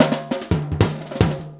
LOOP39SD03-R.wav